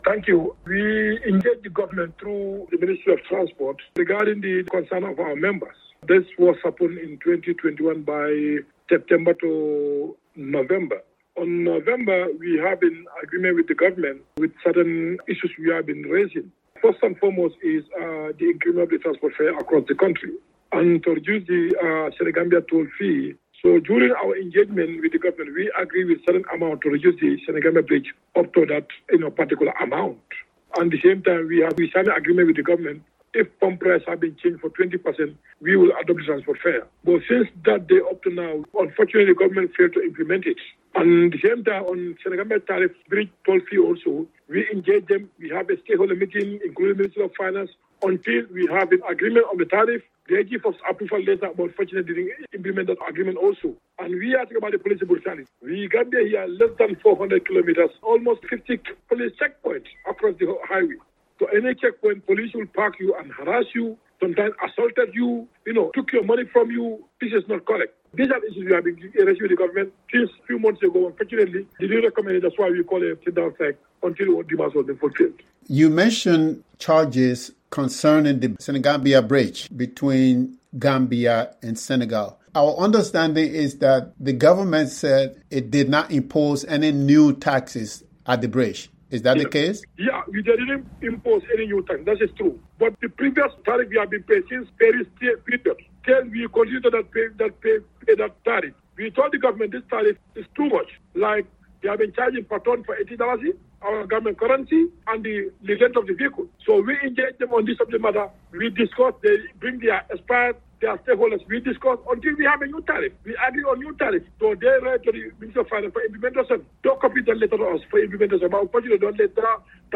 The interview was edited for brevity and clarity.